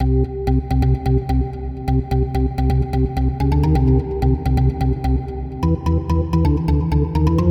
描述：以128BPM创建。风琴节拍循环。
Tag: 128 bpm House Loops Synth Loops 1.26 MB wav Key : Unknown